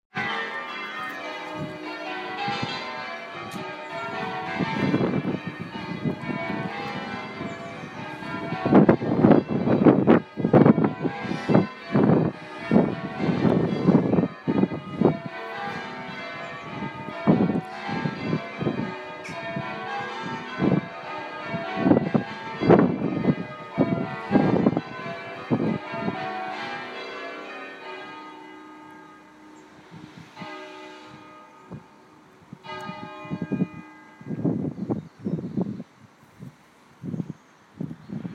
Church bells
St Mary's church bells ringing before service